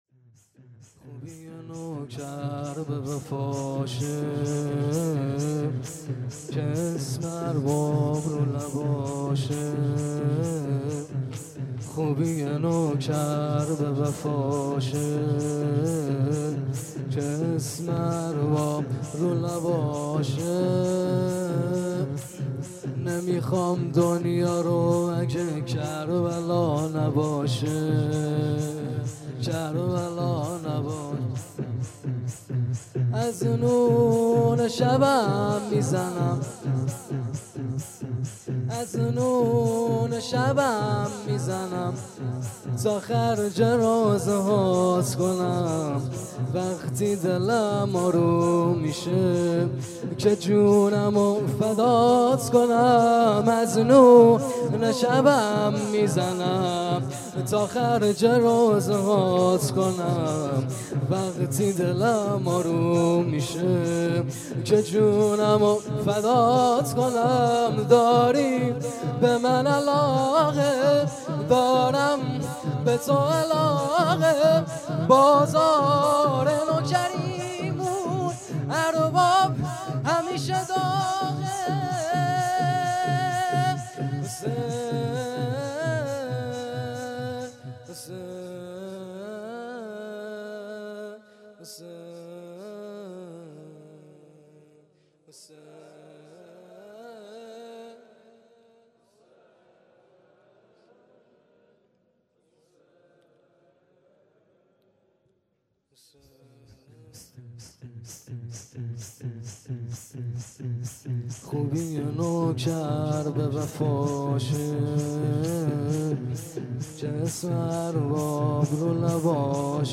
شور پایانی | خوبی نوکر به وفاشه